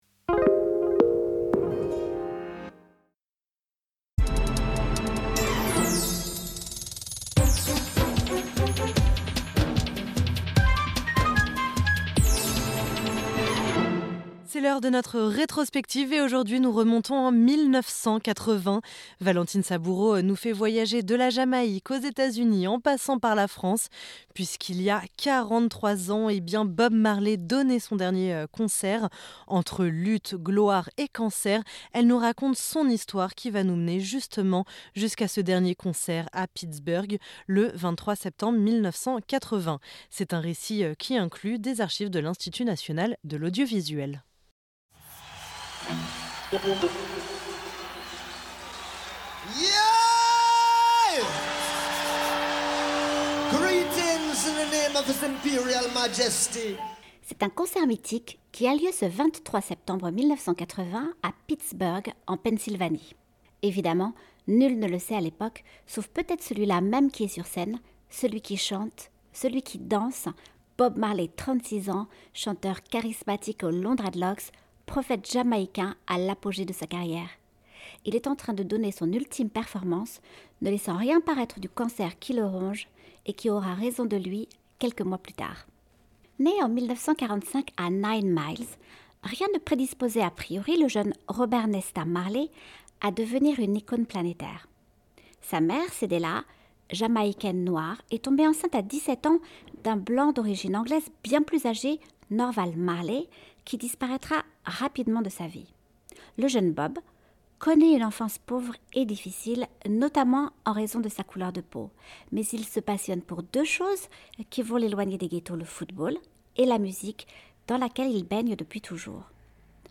C’est un récit qui inclut des archives de l’Institut National de l’Audiovisuel.